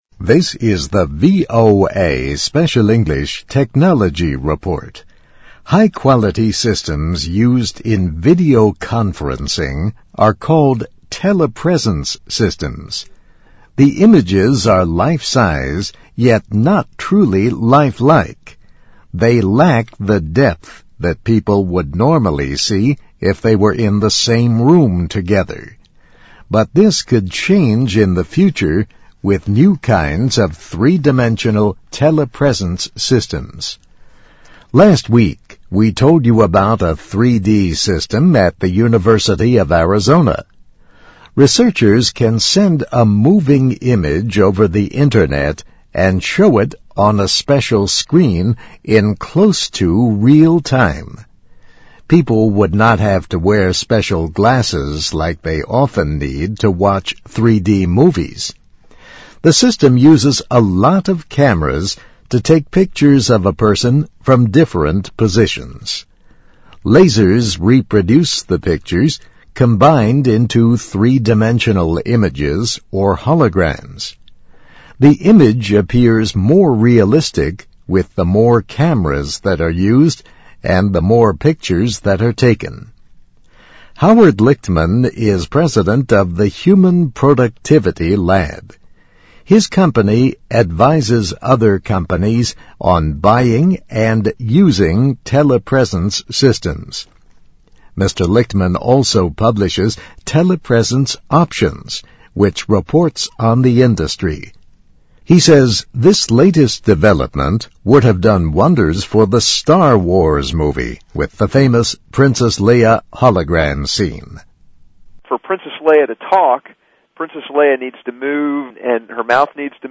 VOA慢速英语2010年-Technology Report - Taking a Closer Lo 听力文件下载—在线英语听力室